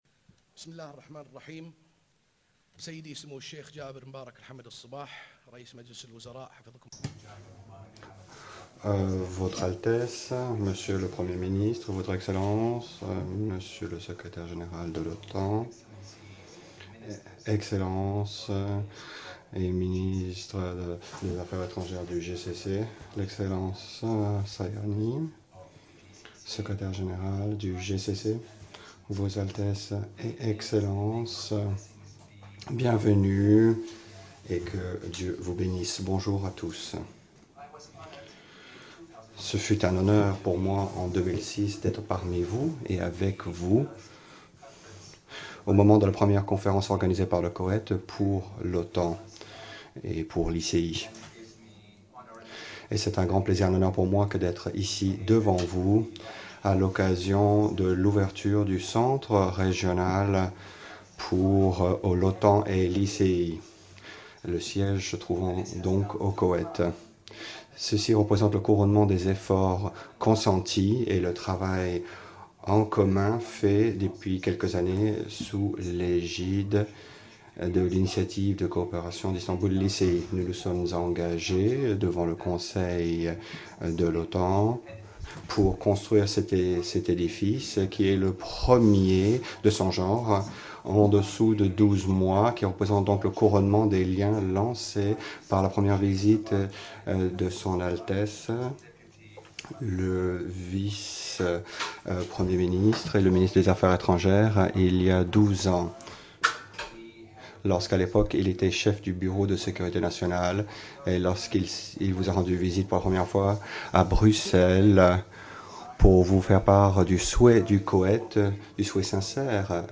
Opening remarks by NATO Secretary General Jens Stoltenberg at the inauguration ceremony of the NATO Istanbul Cooperation Initiative (ICI) Regional Centre, Kuwait City
(As delivered)